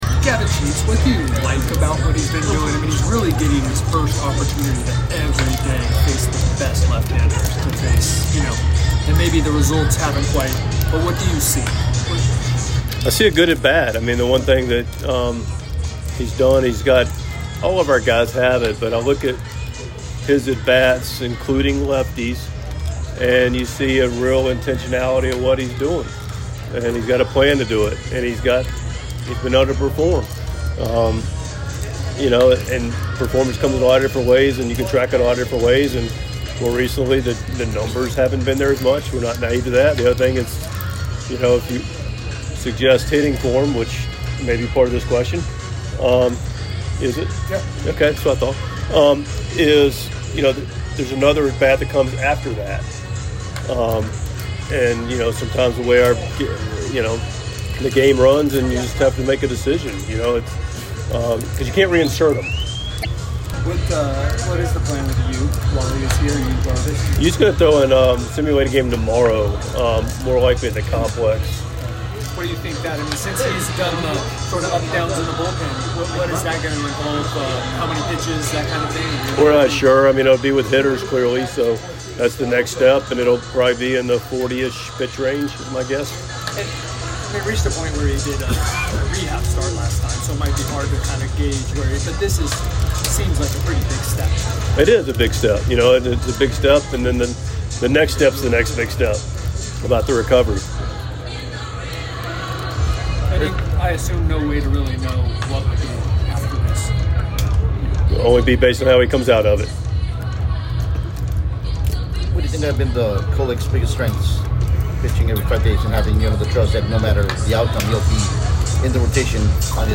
6.13.25 Mike Shildt Pregame Press Conference (at Arizona)
Padres manager Mike Shildt speaks with the media before the team's game against the Arizona Diamondbacks on Friday evening.